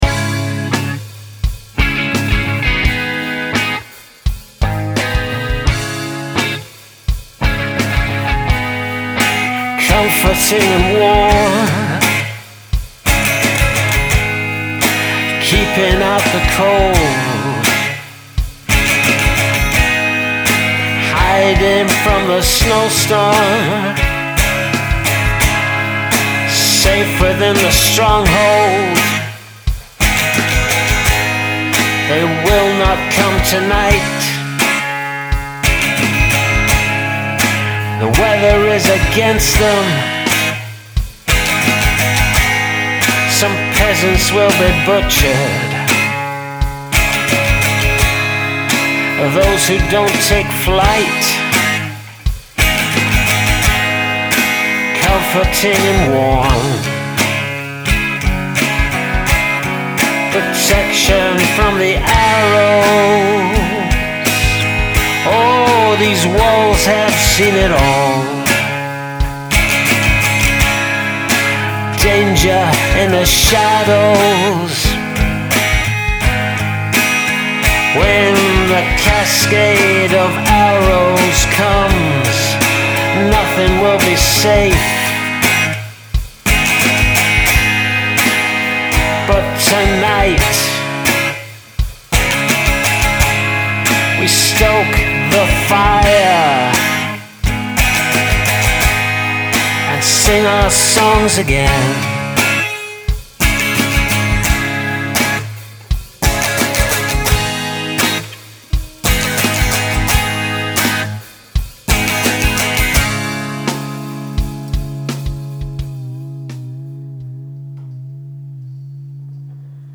This song RAWKS!